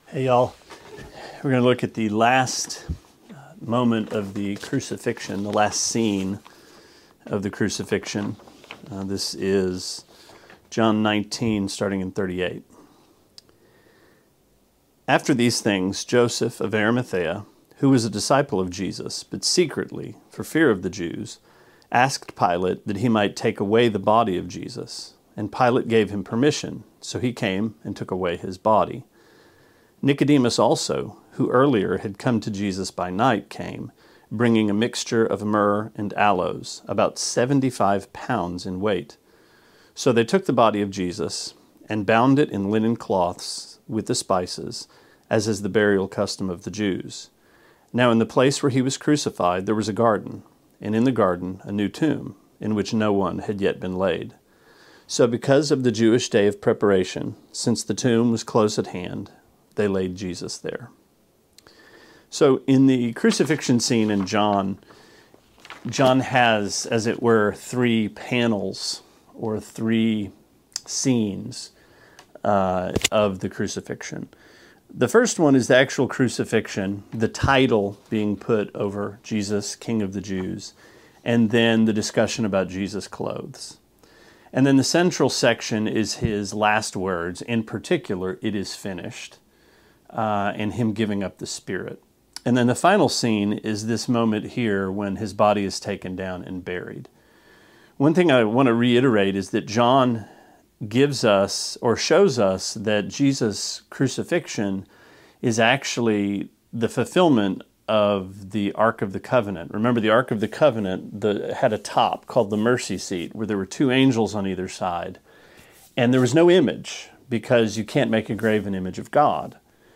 Sermonette 6/10: John 19:38-42: Tomb to Womb